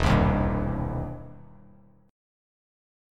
EmM7#5 chord